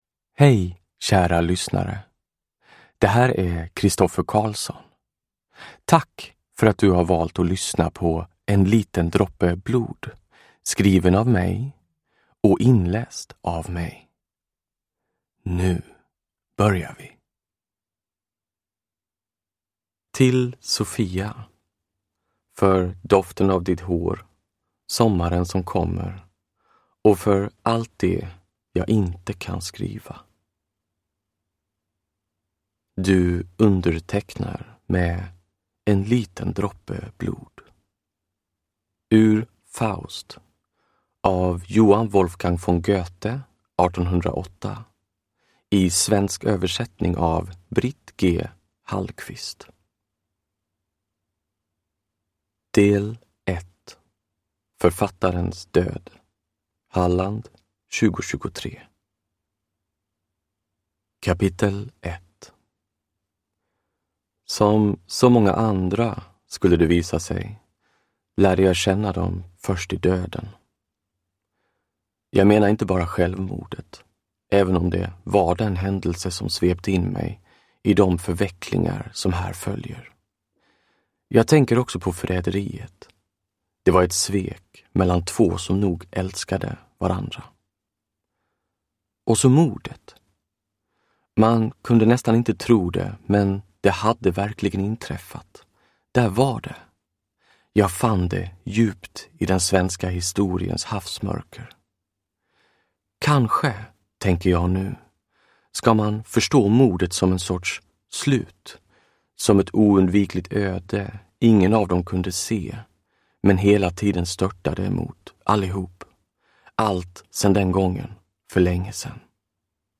En liten droppe blod (ljudbok) av Christoffer Carlsson